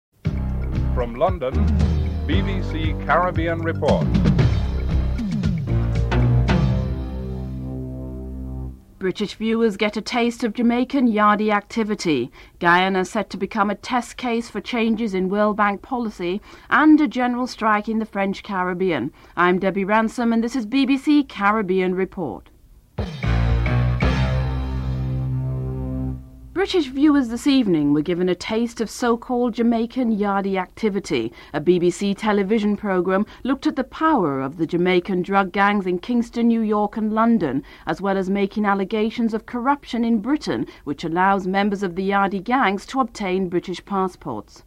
Jean-Bertrand gives his views on tightened sanctions against Haiti and the need for international support in order for these sanctions to work(9:51-12:38)
Wrap up and theme music(14:49-15:02)